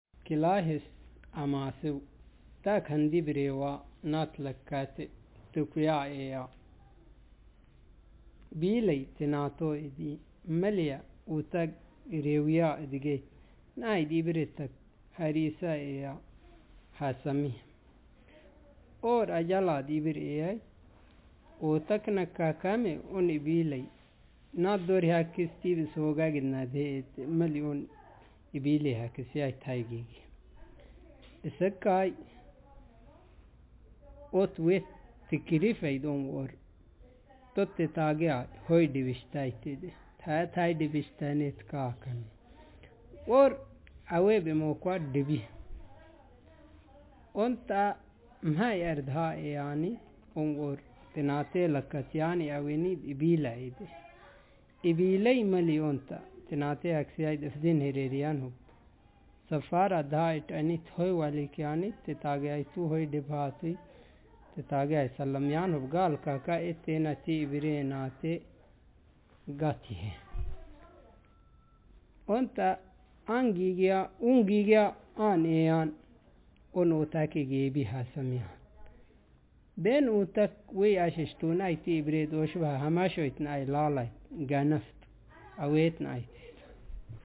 Speaker sexm
Text genrestimulus retelling